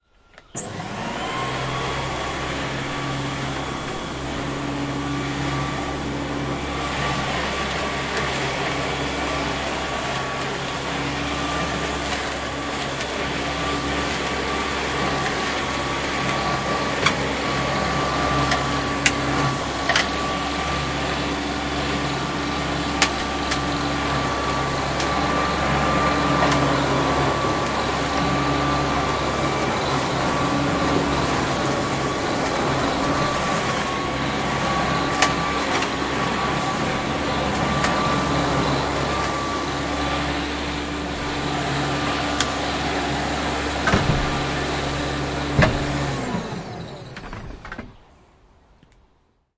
用推式卷轴割草机修剪草坪
无需担心发动机噪音，只需在卷轴上转动刀片并切割草坪。
Tag: 切割机 卷筒 没有 割草机 燃气 供电 割草机 修剪 草坪